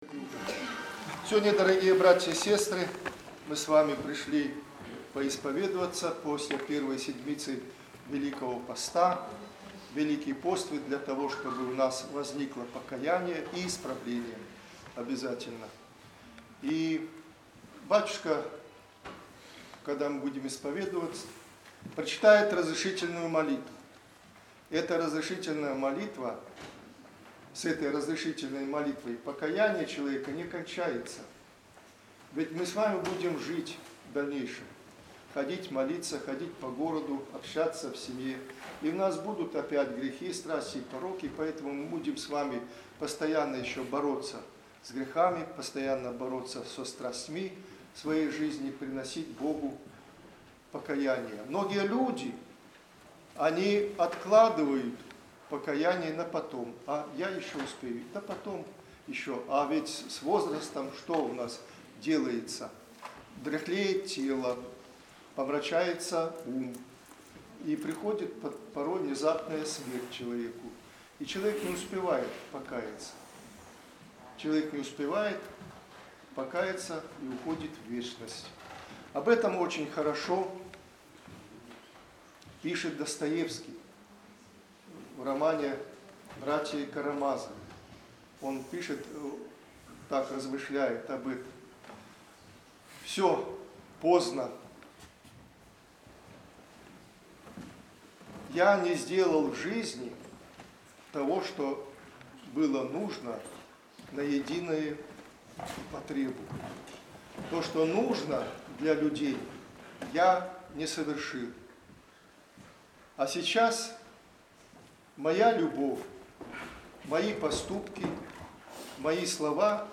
Вечером в пятницу первой недели Великого поста в Екатерининском храме был совершен чин общей исповеди, предварила который Утреня.